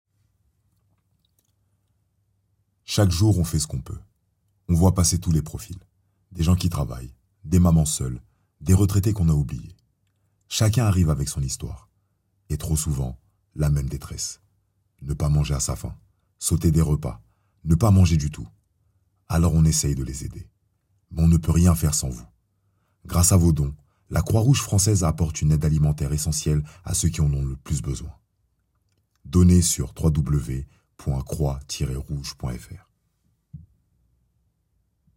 30 - 55 ans - Basse Baryton-basse